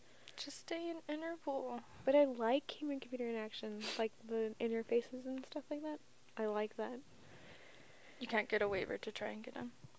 Examples with Bookended Narrow Pitch